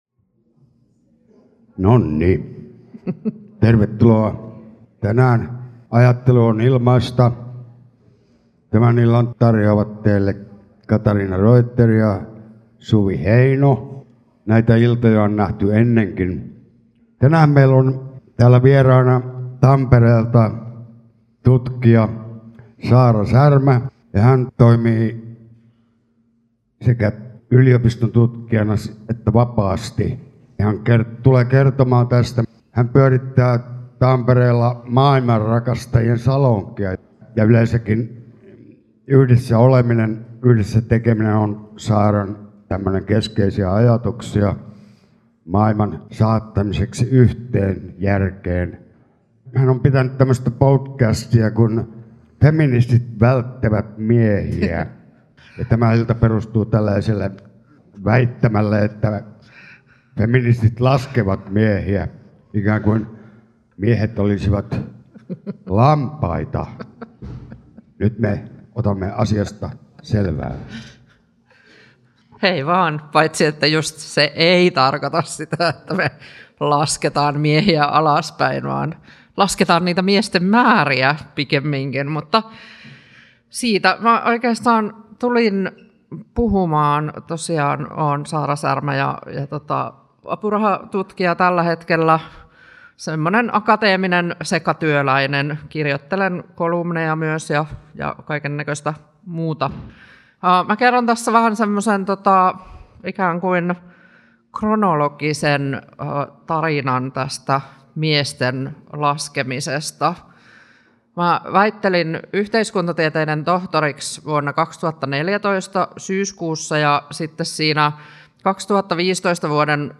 Ajattelu on ilmaista -tapahtumassa pohdittiin sateisessa marraskuussa, miksi feministit laskevat miehiä.